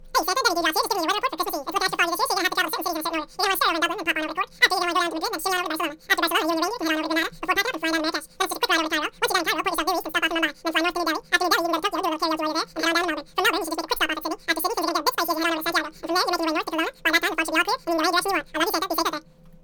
Click here to download this year's Christmas Eve weather report! It looks like it's being broadcasted in Elvish!
weather-report.mp3